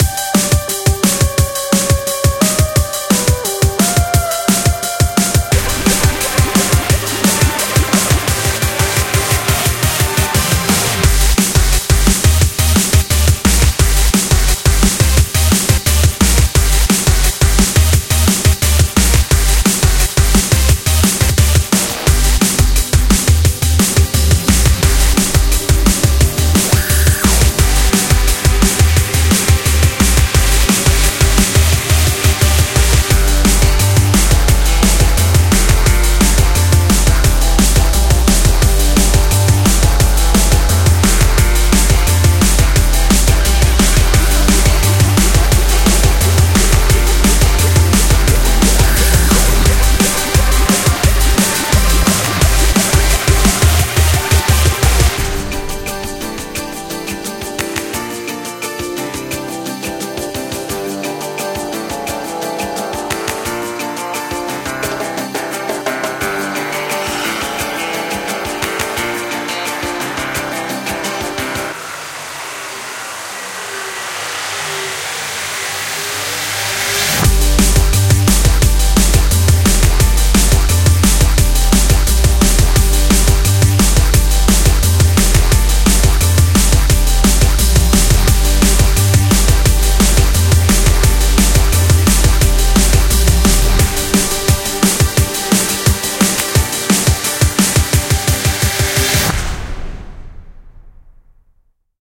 BPM174
Audio QualityPerfect (High Quality)